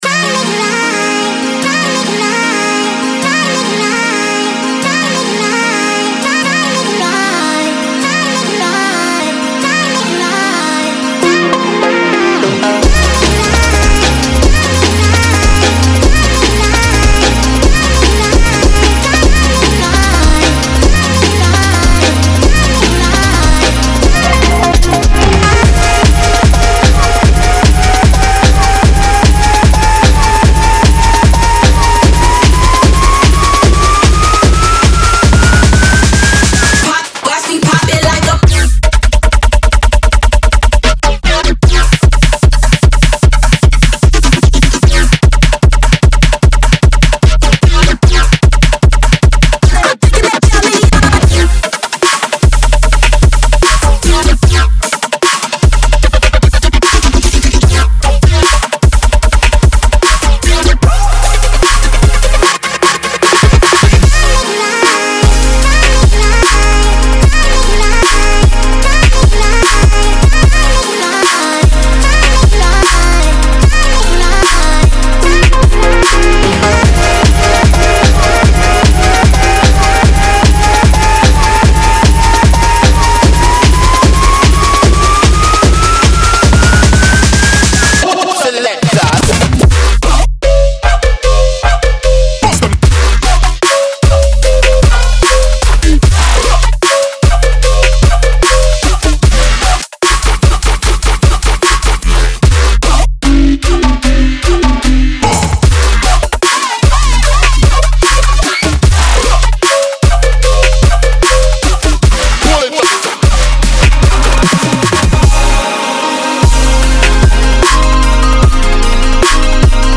BPM150-150
Audio QualityPerfect (High Quality)
Dubstep song for StepMania, ITGmania, Project Outfox
Full Length Song (not arcade length cut)